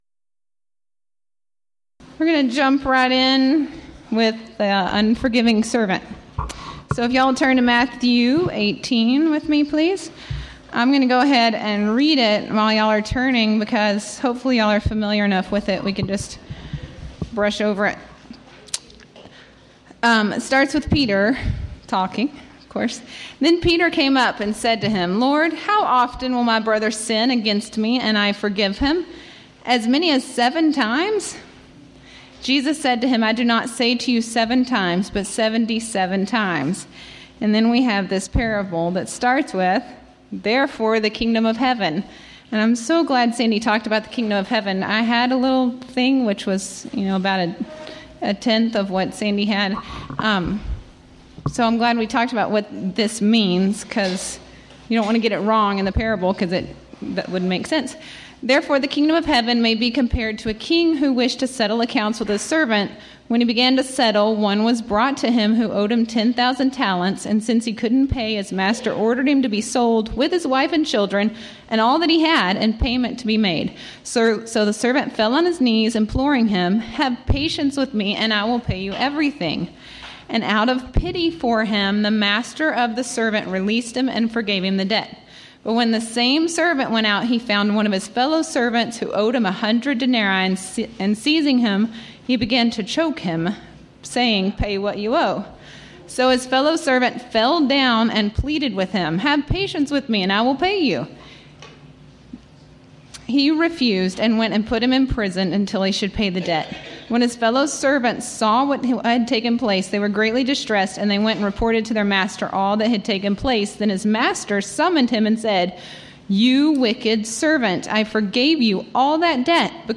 Event: 9th Annual Texas Ladies in Christ Retreat Theme/Title: Studies in Parables
Ladies Sessions